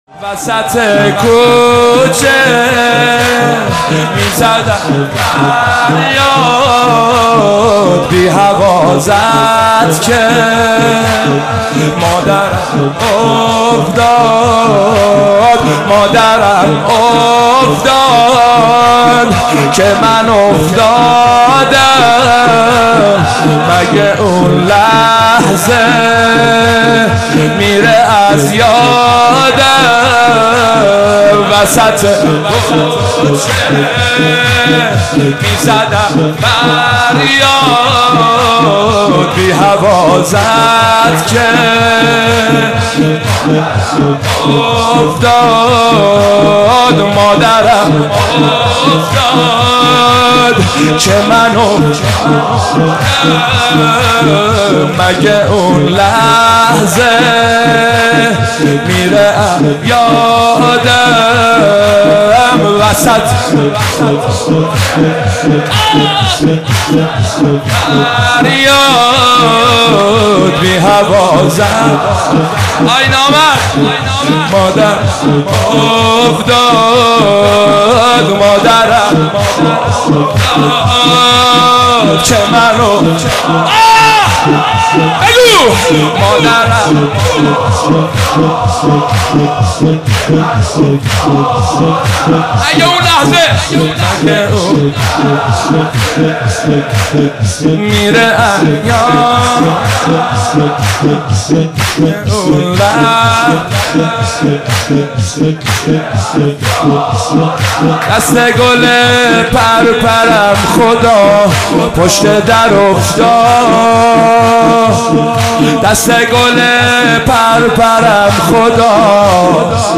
مداحی وسط کوچه میزدم فریاد سیب سرخی